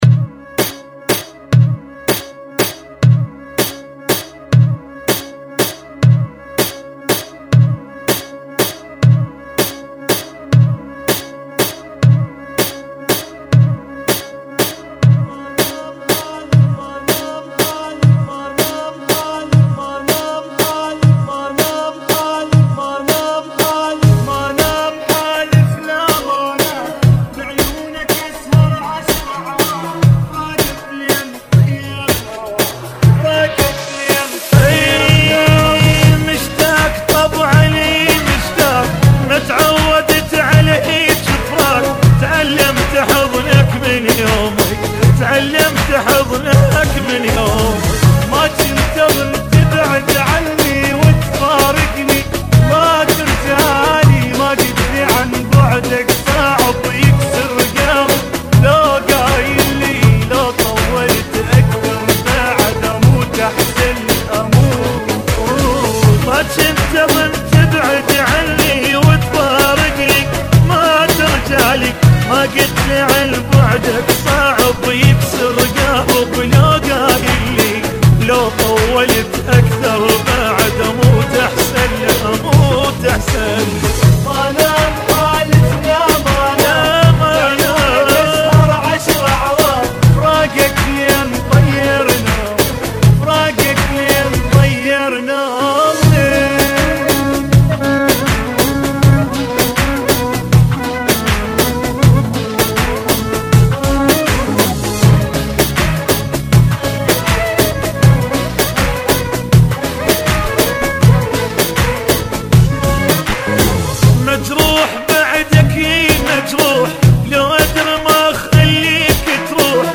[ 80 Bpm ]